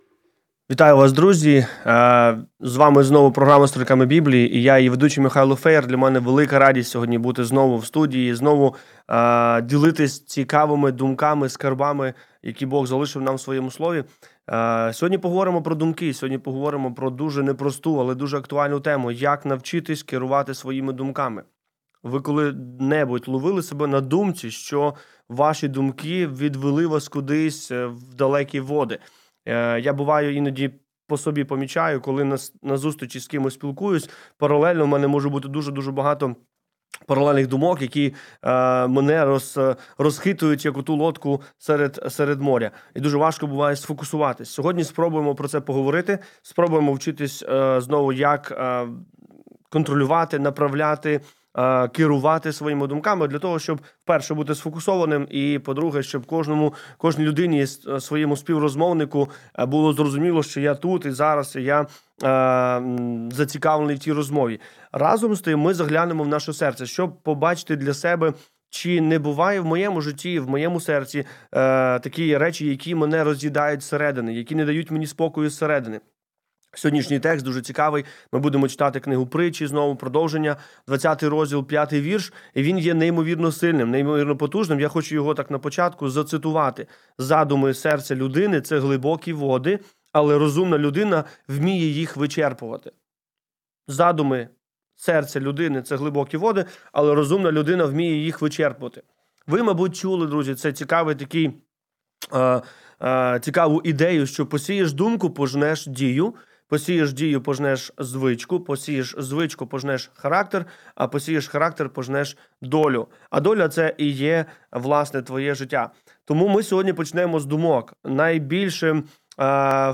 Завантажати запис ефіру на тему: Як навчитися керувати своїми думками?